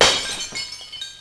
glass8.wav